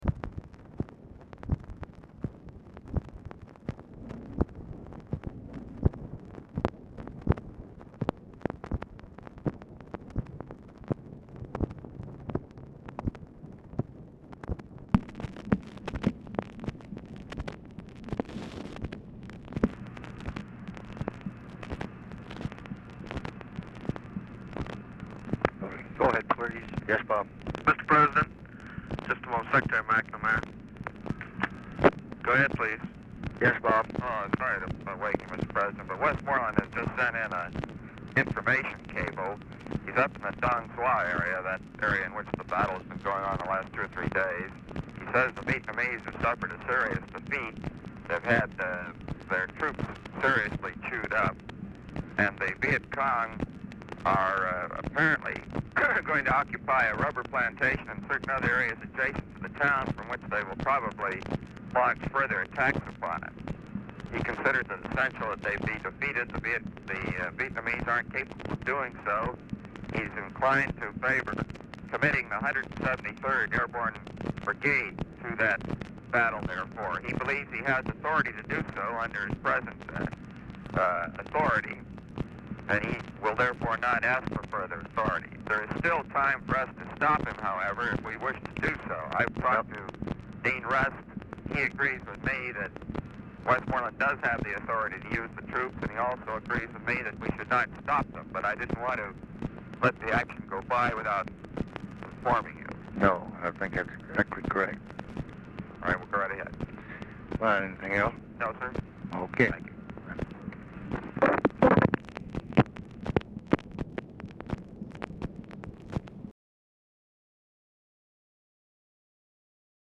Telephone conversation # 8129, sound recording, LBJ and ROBERT MCNAMARA, 6/13/1965, 2:45AM | Discover LBJ
Format Dictation belt
Location Of Speaker 1 LBJ Ranch, near Stonewall, Texas
Other Speaker(s) SIGNAL CORPS OPERATOR